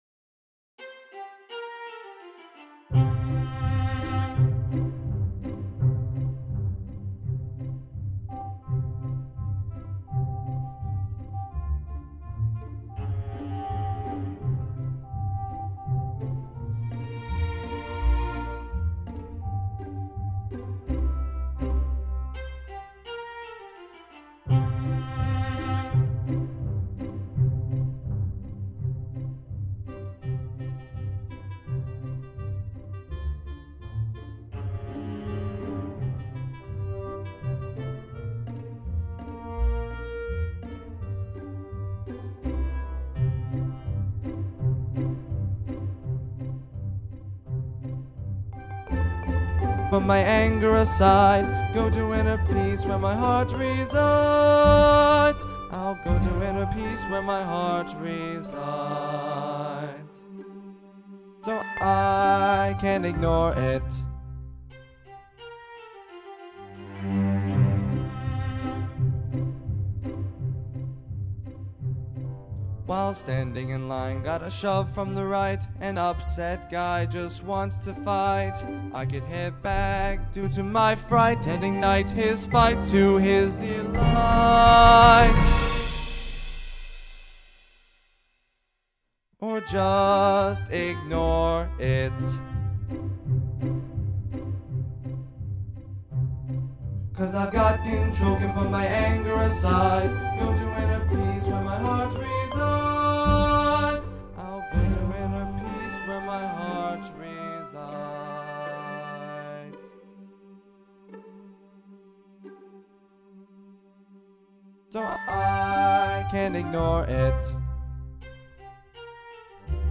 MP3  Accompaniment (no singing)